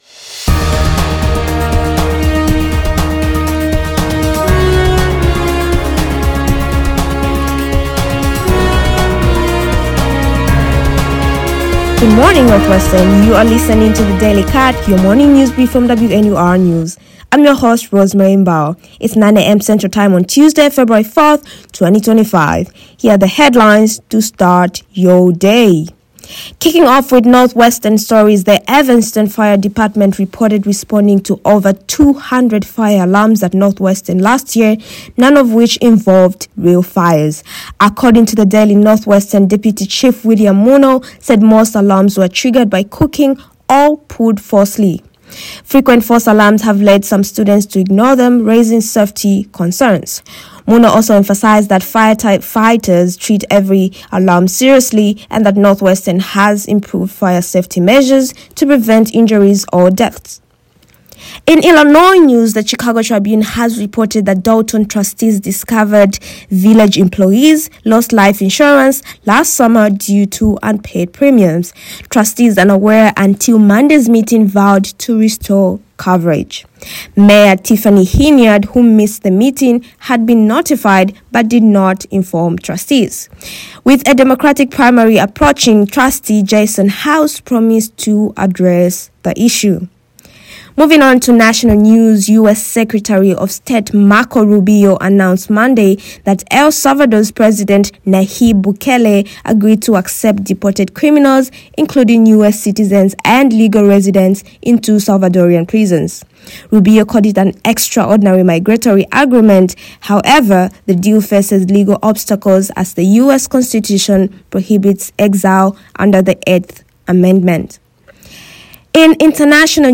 2/3/25 Evanston fire department, Dolton employees, El Salvador prison, China Tariffs WNUR News broadcasts live at 6 pm CST on Mondays, Wednesdays, and Fridays on WNUR 89.3 FM.